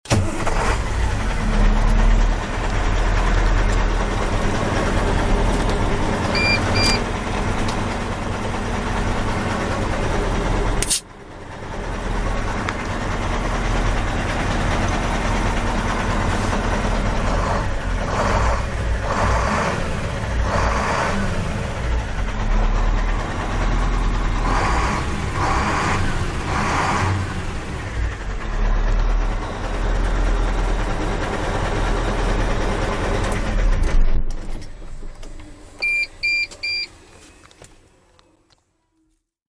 Звуки грузовиков
Звук КАМАЗа из кабины: заводится, работает и глушит двигатель